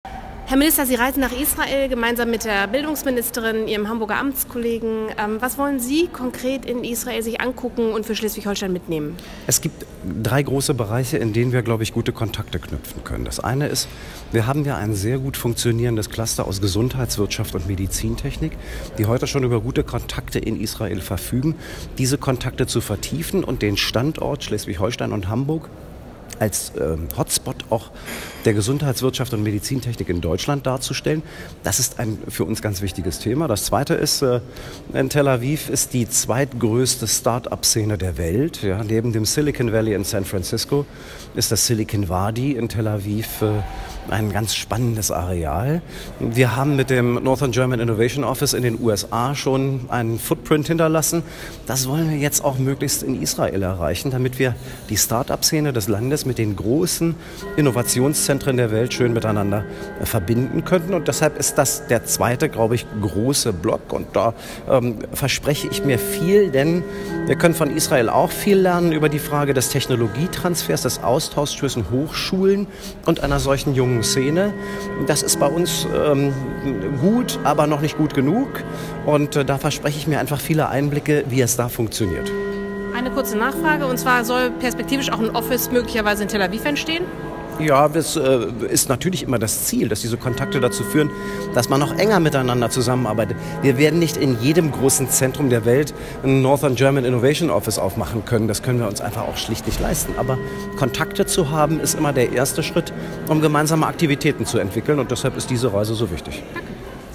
Im Gespräch mit dem NDR-Hörfunk sagte Buchholz weiter (Pfeil klicken)